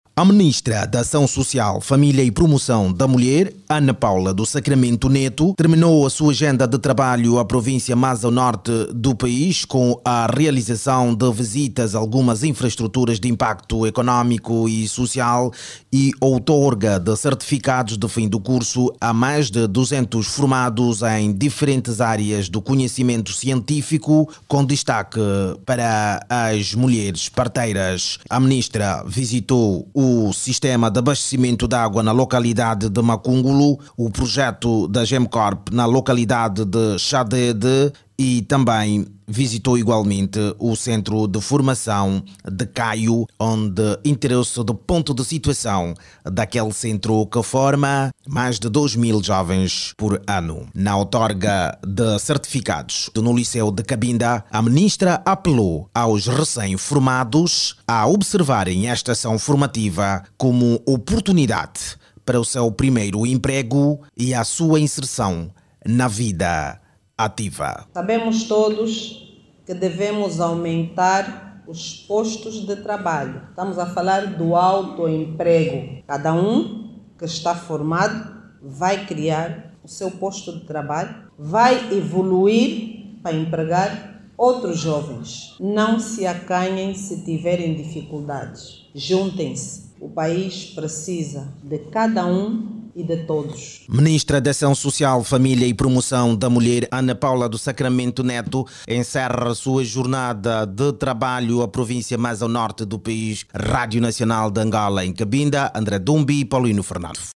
A Ministra da Acção Social Família e Promoção da Mulher, reafirmou em Cabinda o compromisso do governo no fomento do autoemprego. Ana Paula de Sacramento Neto, falava durante a cerimónia de entrega de certificados a mais de Duzentos jovens formados em varias especialidades profissionais com realce para mulheres parteiras. Ouça no áudio abaixo toda informação com a reportagem